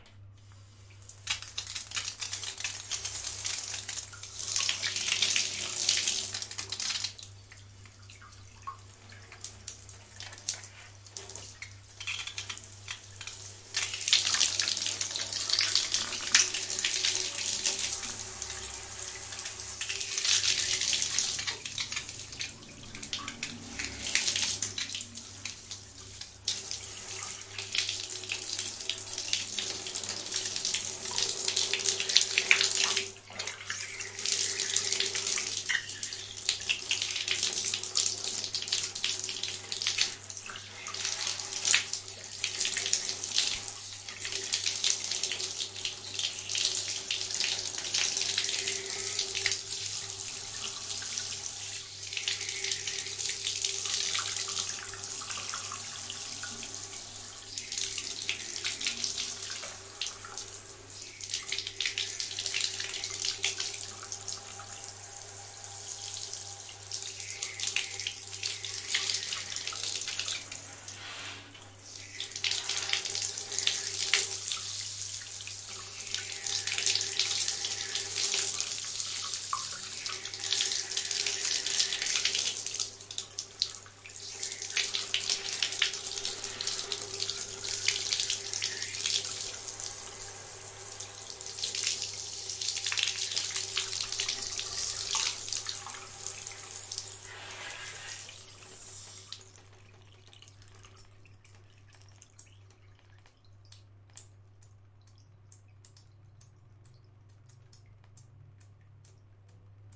洗澡水
描述：浴缸水运行。打开水龙头，让水流动，然后关掉水龙头。
标签： 浴缸 浴缸 浴缸 浴室 排水 水龙头
声道立体声